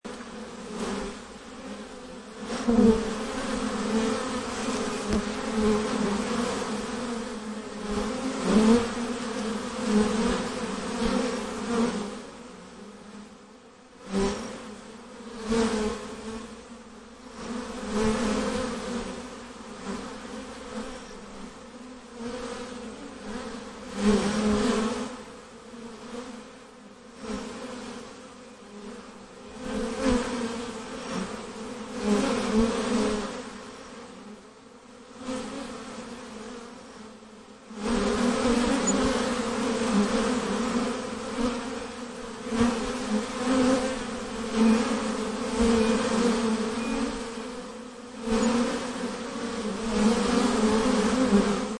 Download Honey Bees sound effect for free.
Honey Bees